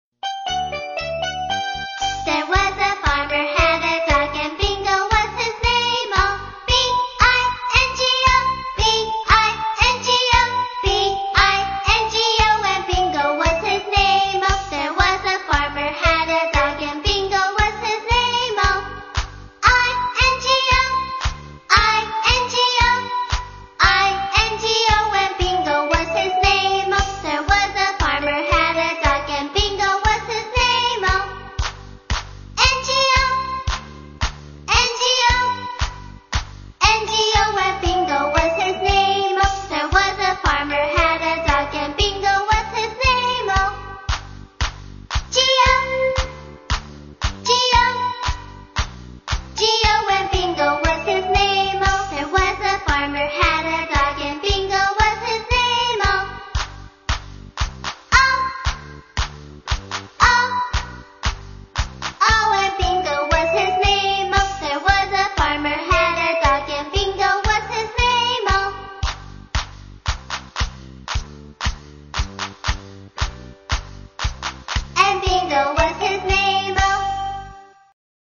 在线英语听力室英语儿歌274首 第18期:Bingo的听力文件下载,收录了274首发音地道纯正，音乐节奏活泼动人的英文儿歌，从小培养对英语的爱好，为以后萌娃学习更多的英语知识，打下坚实的基础。